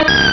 Cri de Toudoudou dans Pokémon Rubis et Saphir.